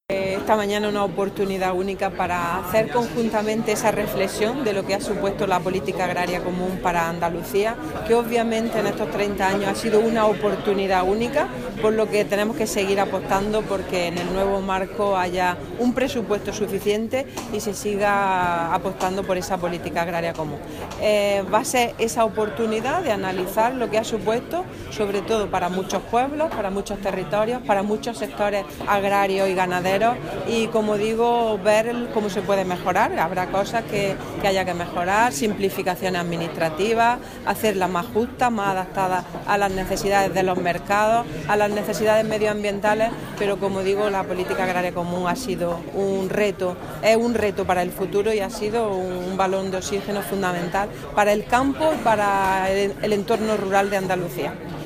Declaraciones de Carmen Ortiz sobre la jornada '30 años de PAC en España'